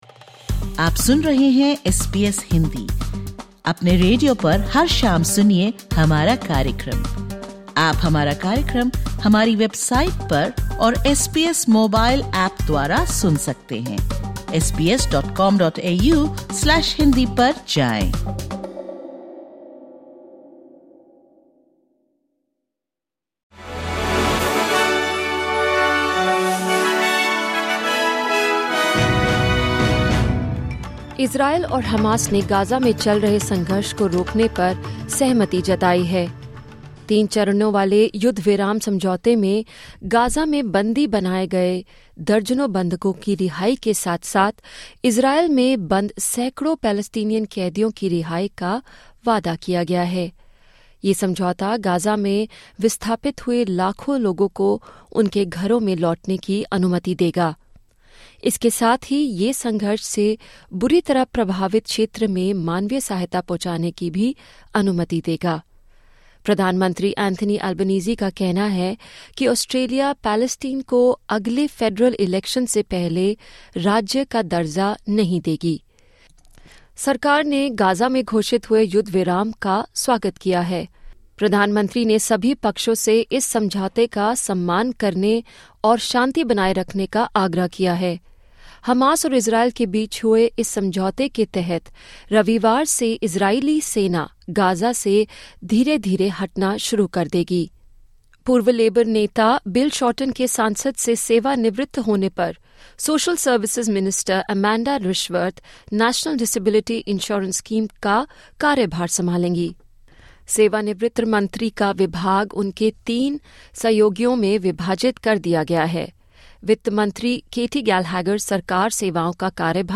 सुनें ऑस्ट्रेलिया और भारत से 16/01/2025 की प्रमुख खबरें।